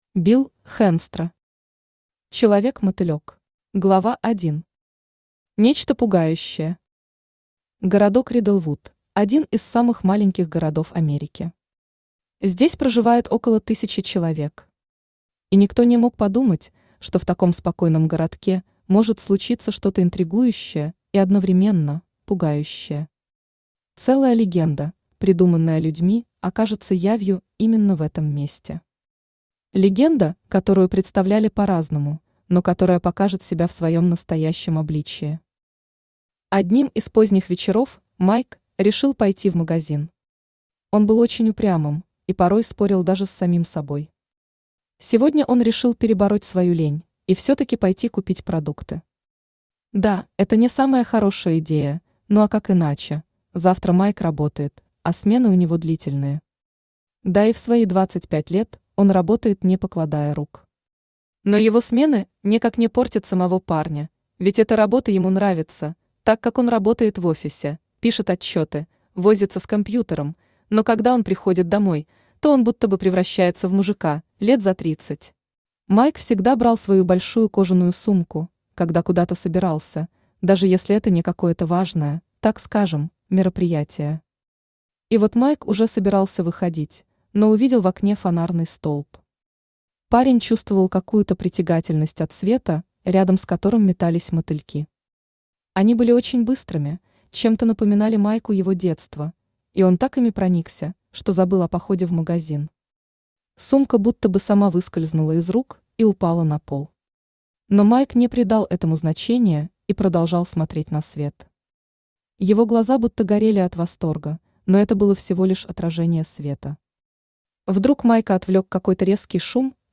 Аудиокнига Человек-Мотылек | Библиотека аудиокниг
Aудиокнига Человек-Мотылек Автор Билл Ди Хэнстро Читает аудиокнигу Авточтец ЛитРес.